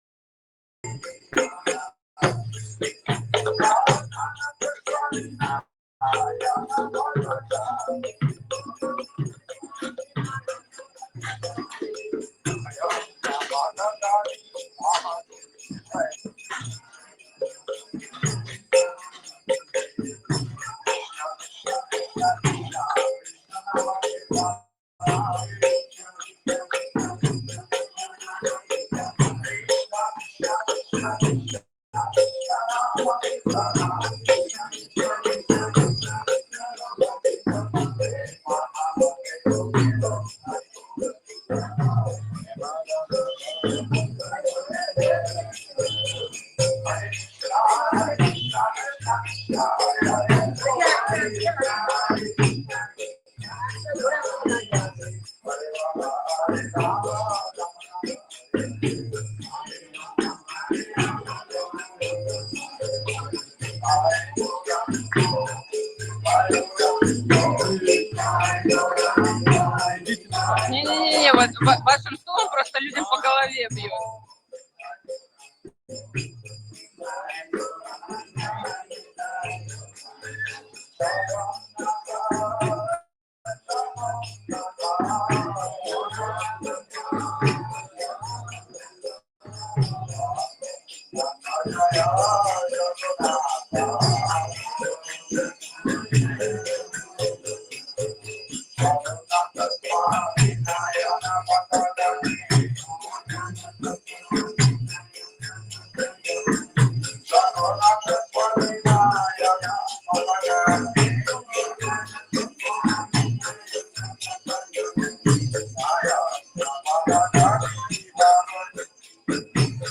скачать (формат MP3, 17 МБ ) Говардхан часть лекции о Радха-кунде лекция на берегу Уддхава-кунды лекция на крыльце дома Бхактивинода Тхакура, недалеко от Радха-кунды Нарада-кунда